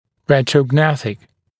[ˌretrə(u)ˈgnæθɪk][ˌрэтро(у)’гнэсик]ретрогнатический